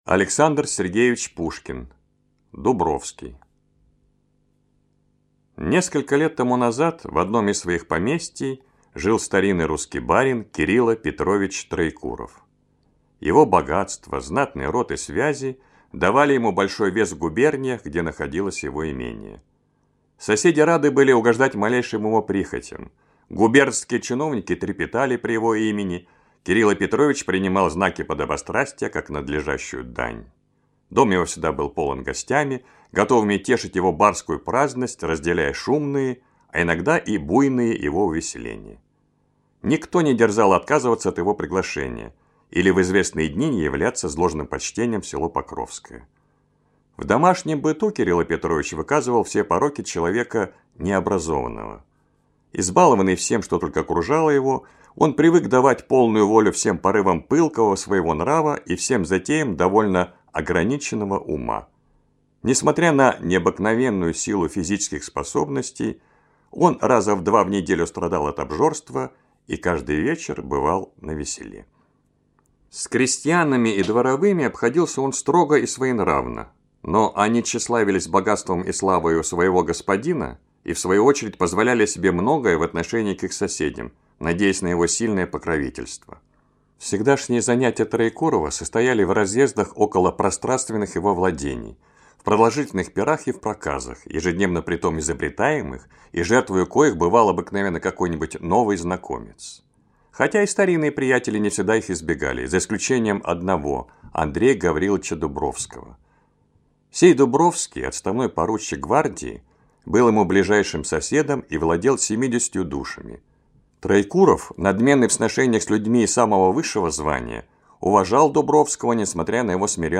Дубровский - аудио повесть Пушкина - слушать онлайн